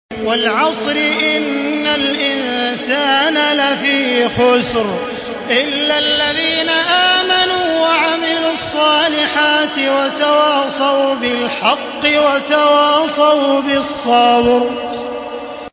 سورة العصر MP3 بصوت تراويح الحرم المكي 1435 برواية حفص
مرتل